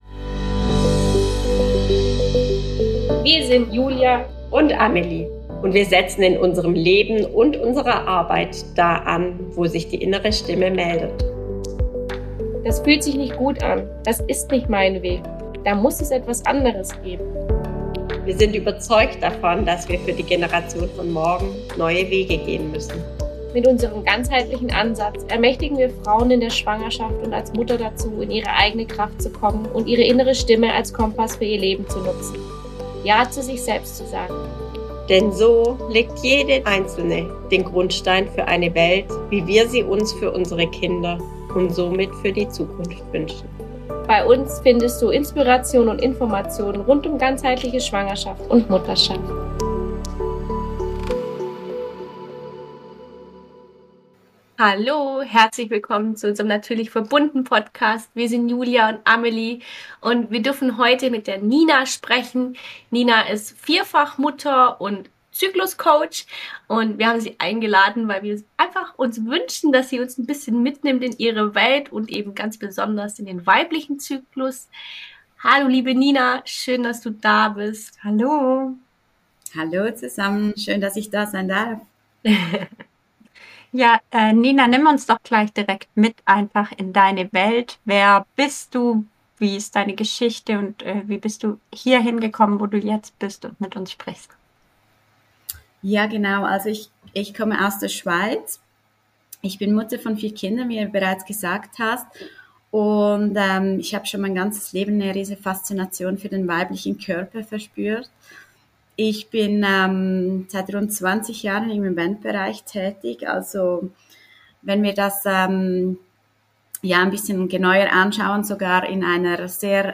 Beschreibung vor 2 Jahren Ein super interessantes Gespräch über den weiblichen Zyklus und wie wir alle, egal ob Mann oder Frau lernen dürfen es wieder als natürlichen und wertvollen Teil der Frau zu erkennen und zu schätzen.